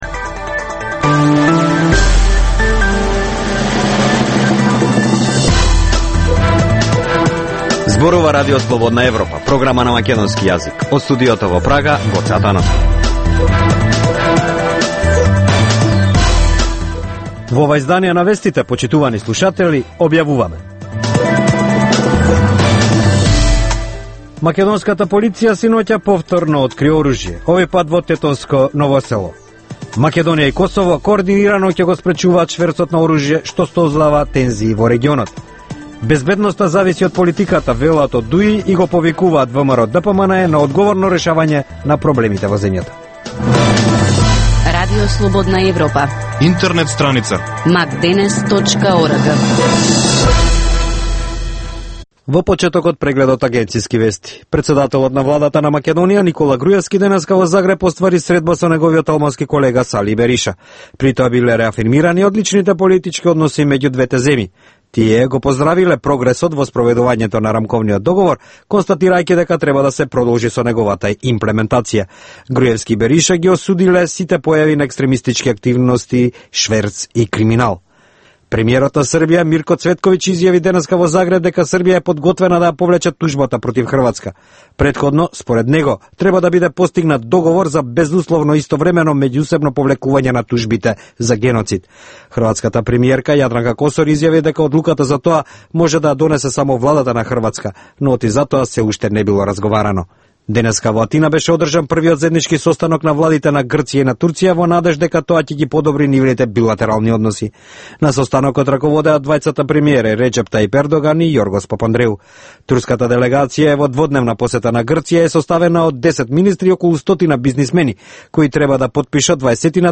Преглед на вестите и актуелностите од Македонија и светот, како и локални теми од земјата од студиото во Прага.